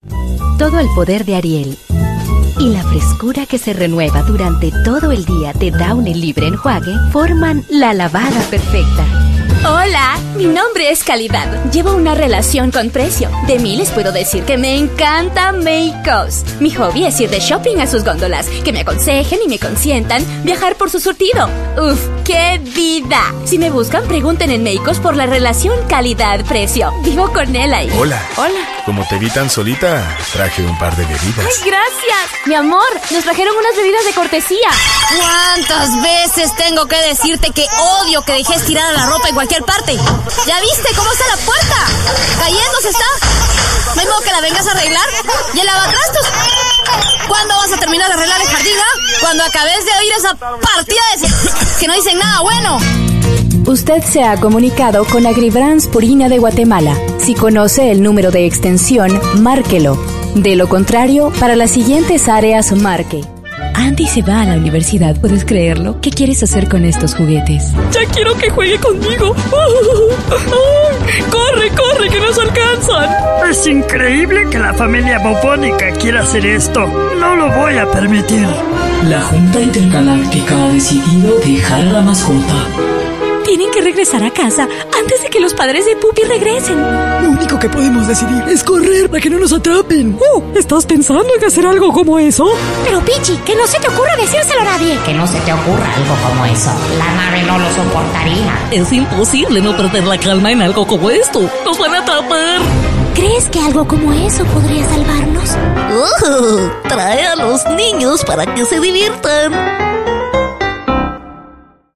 Locutora comercial, documentales, cabina radial, etc. Actriz en teatro y cine.
Sprechprobe: Werbung (Muttersprache):
Voice over (commercial, documentary, radio). Actress (Theater and movies)